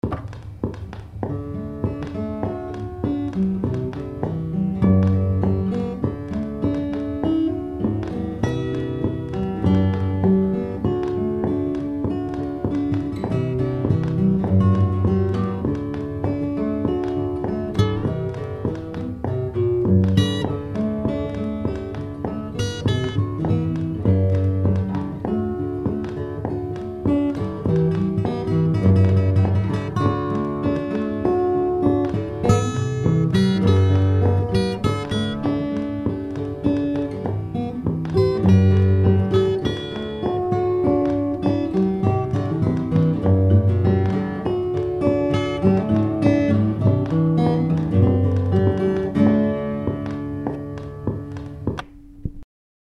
quiet guitar or piano ruminations